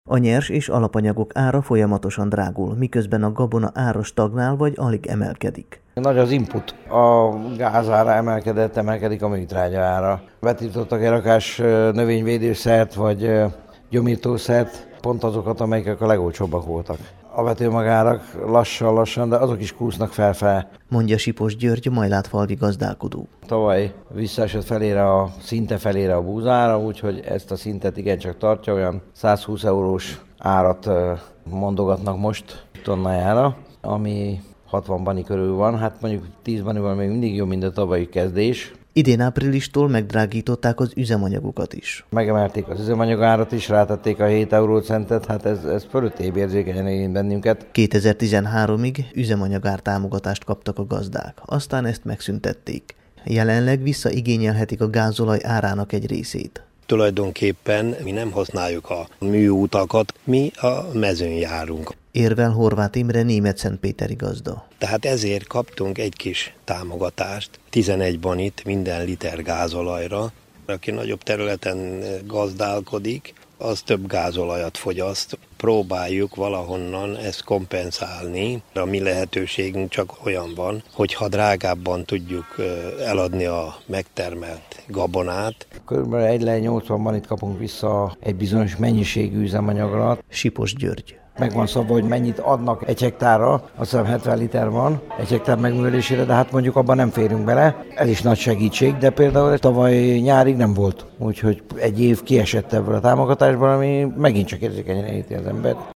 a Temesvári Rádió számára készült